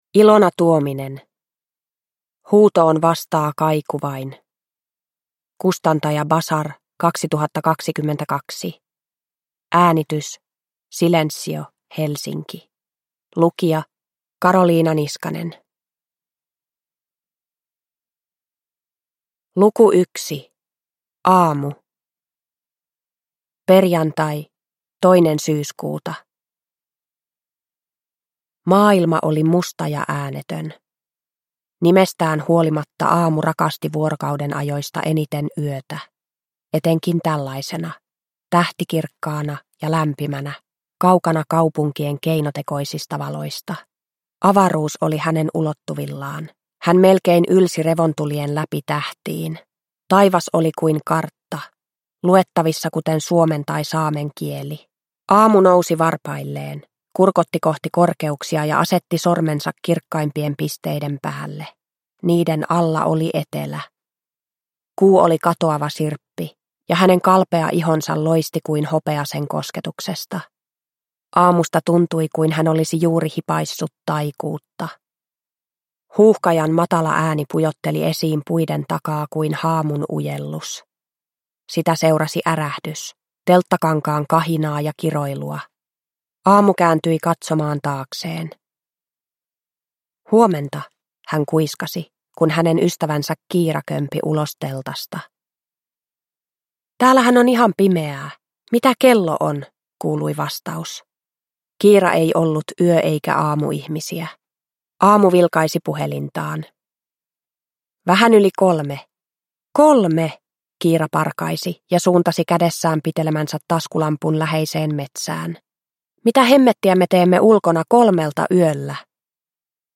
Huutoon vastaa kaiku vain – Ljudbok – Laddas ner